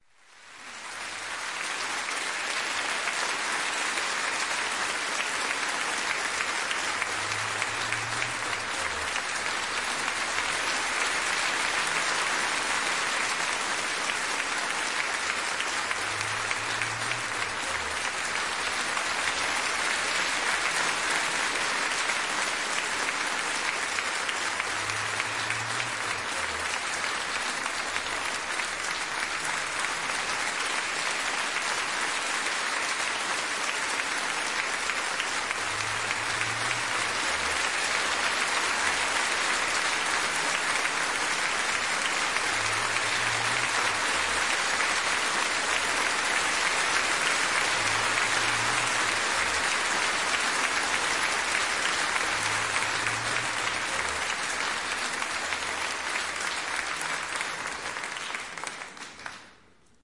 Loud Applause Hall
描述：A high quality stereo recording of crowd applause in a concert hall.
标签： concert designed audience hundreds clapping people crowd fieldrecording
声道立体声